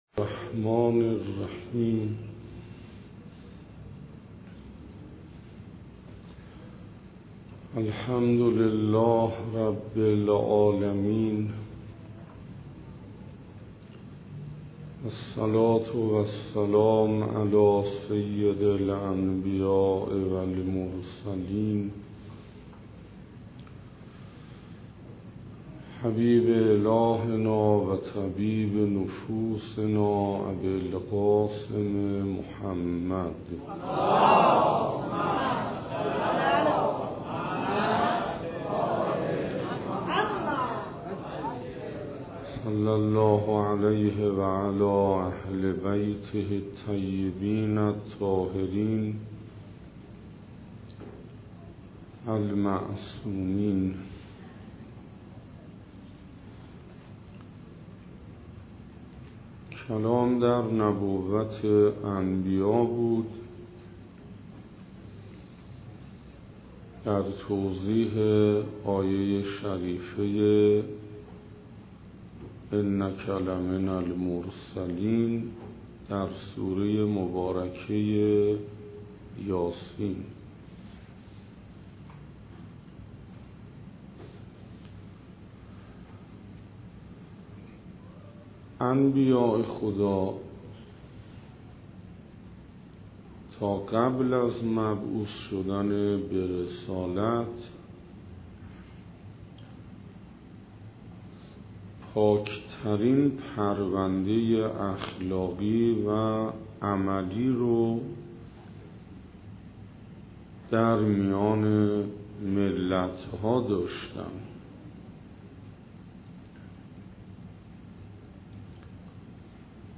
سخنراني يازدهم